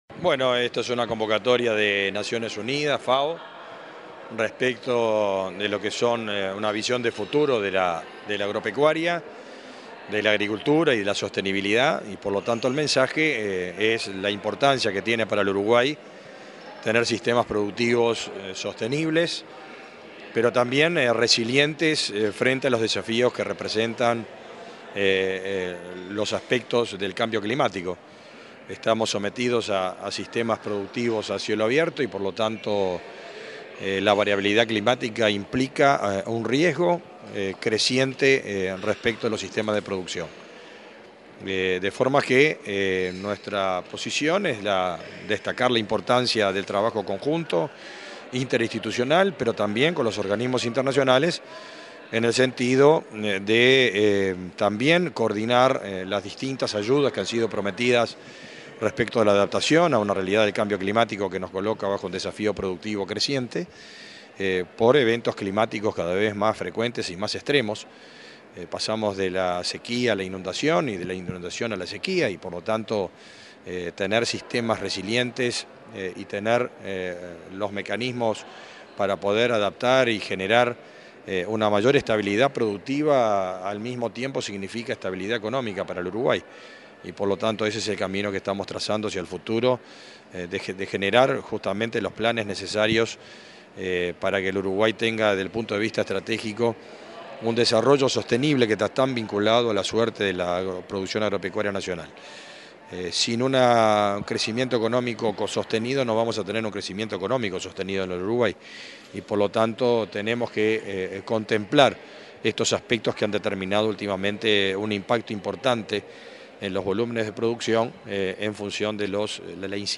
Declaraciones del ministro de Ganadería, Fernando Mattos
Este martes 7, el ministro de Ganadería, Fernando Mattos, dialogó con la prensa en la Torre Ejecutiva, antes de participar en el conversatorio